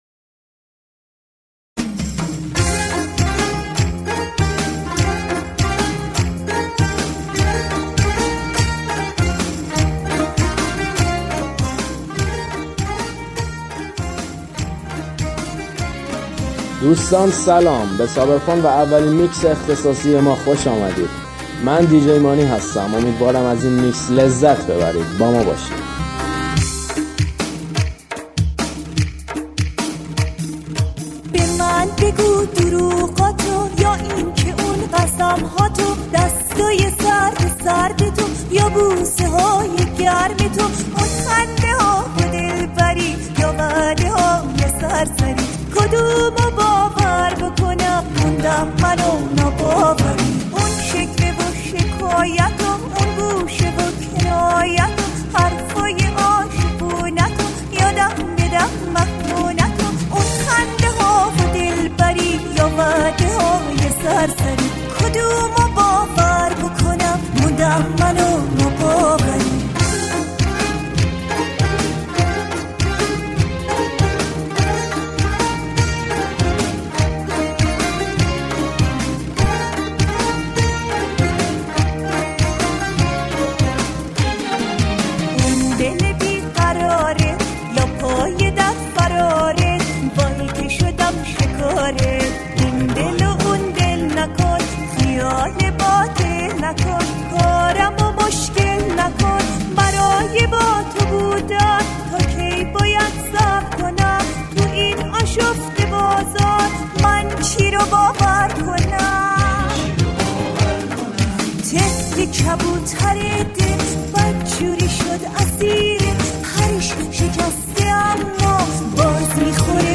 آهنگ شاد قدیمی مخصوص رقص
ریمیکس شاد قدیمی